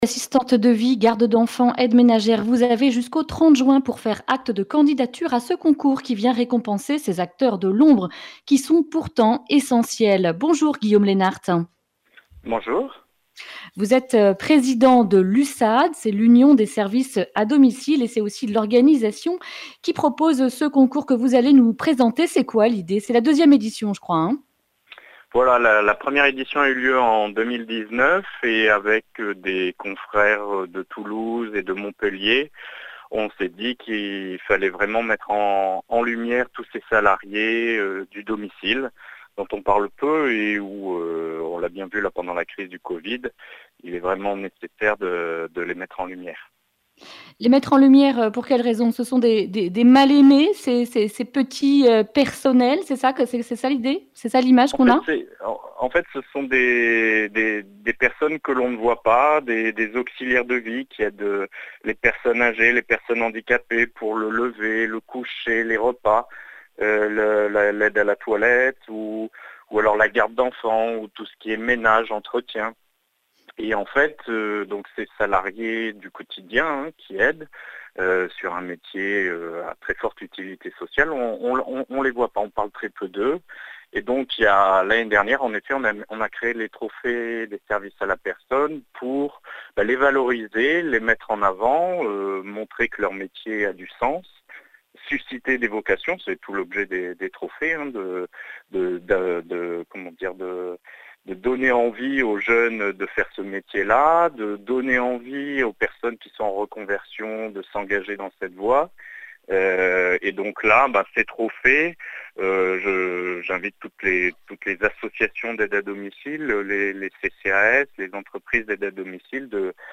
mardi 9 juin 2020 Le grand entretien Durée 11 min